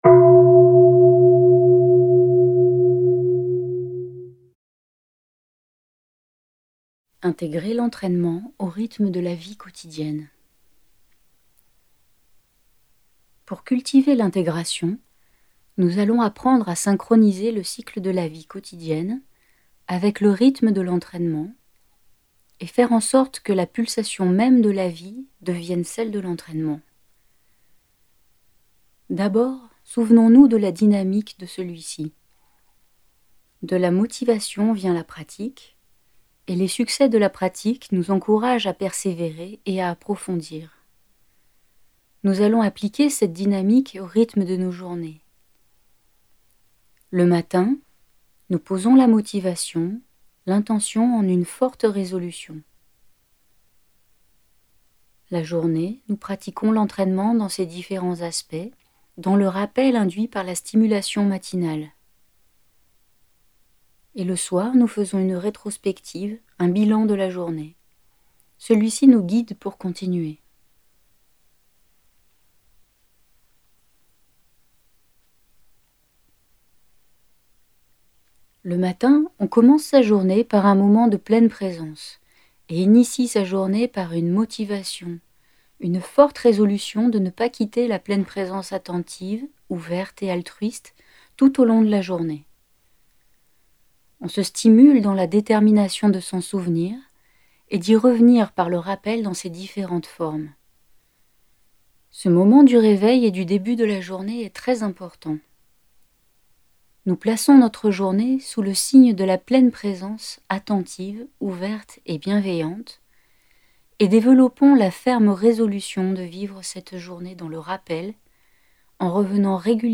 Audio femme
3.ETAPE-7-AUDIO-4-P41-FEMME-.mp3